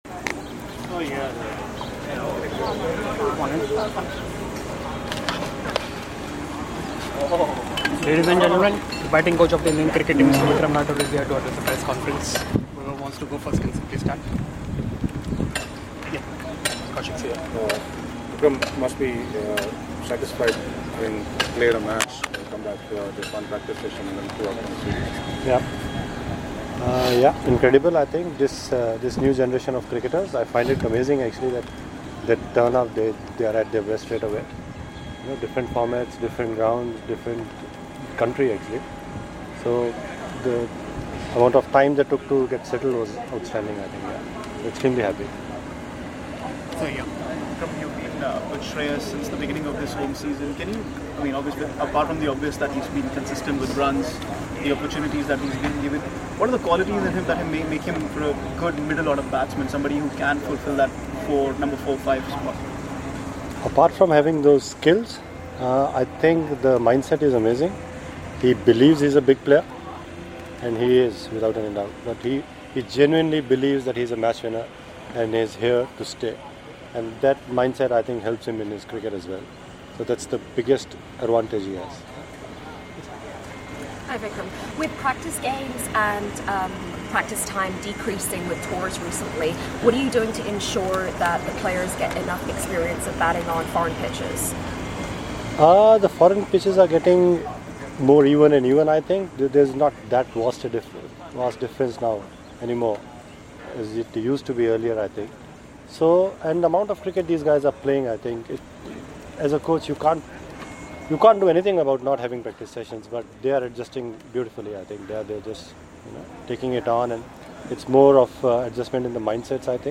Vikram Rathour Speaks Ahead of 3rd T20I vs New Zealand